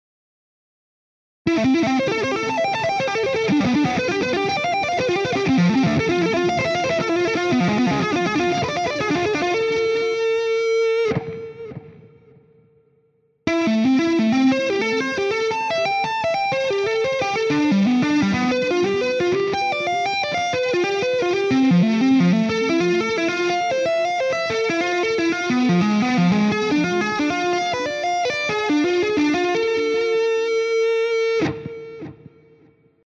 shred-issue132-ex2.mp3